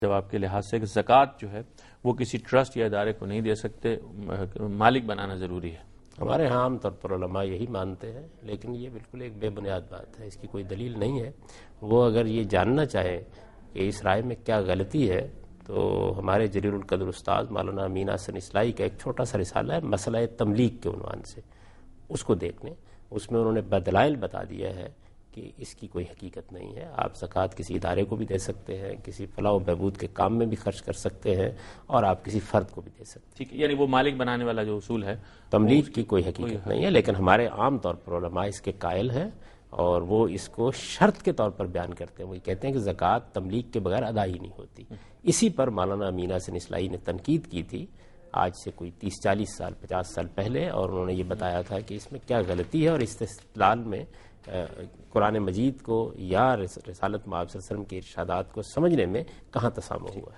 دنیا نیوز کے پروگرام دین و دانش میں جاوید احمد غامدی ”مسئلہ تملیک “ سے متعلق ایک سوال کا جواب دے رہے ہیں